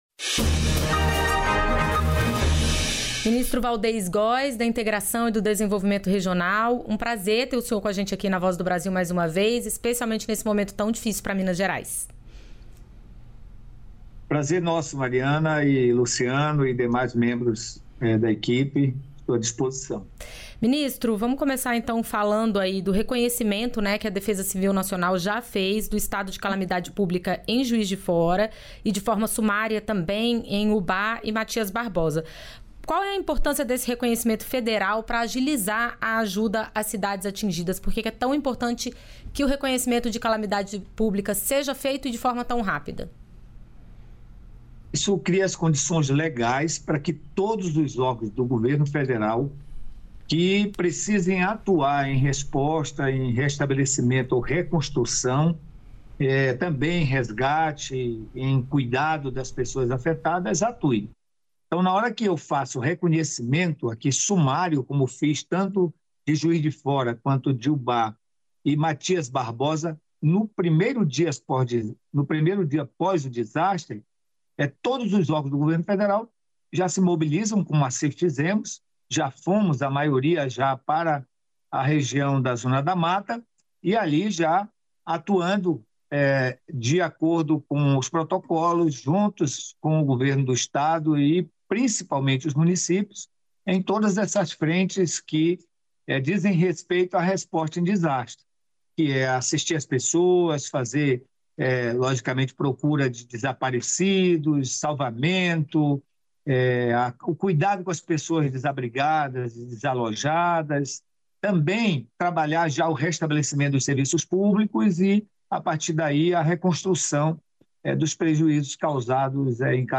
Em entrevista à Voz do Brasil, o ministro fala sobre as ações realizadas no Sudeste para reduzir os danos causados pelas chuvas do fim de semana.
Entrevistas da Voz